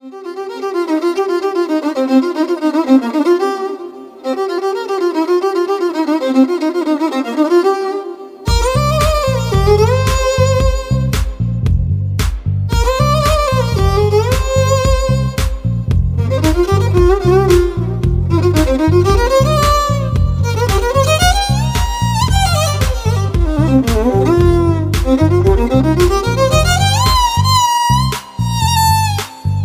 A soulful, hypnotic melody
featuring ethereal vocals and a lush bassline.